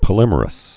(pə-lĭmər-əs)